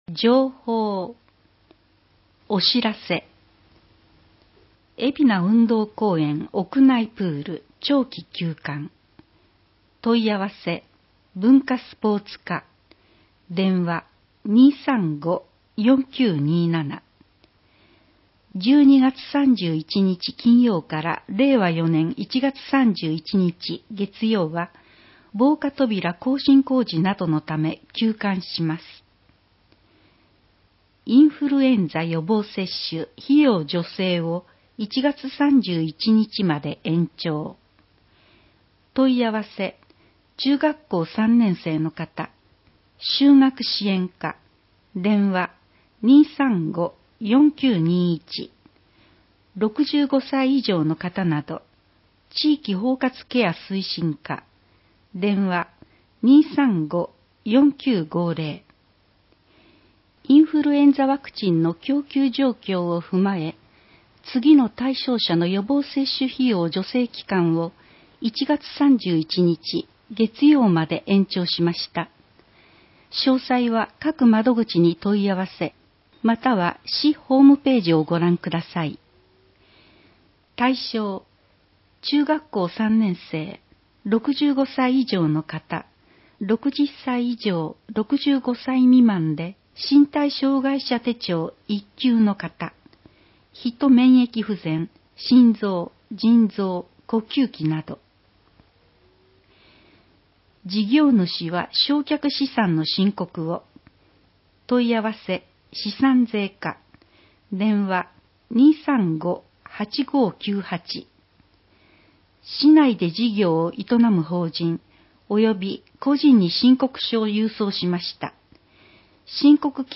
広報えびな 令和3年12月15日号（電子ブック） （外部リンク） PDF・音声版 ※音声版は、音声訳ボランティア「矢ぐるまの会」の協力により、同会が視覚障がい者の方のために作成したものを登載しています。